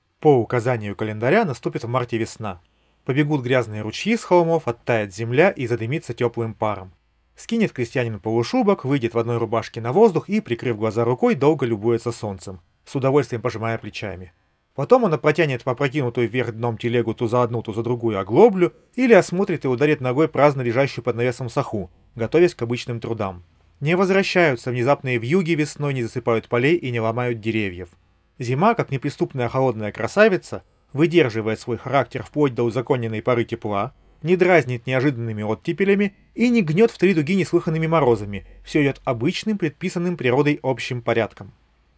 Второй записан на расположенный примерно на том же расстоянии профессиональный рекордер с заведомо высоким качеством записи и приводится для сравнения.
Запись на профессиональный рекордер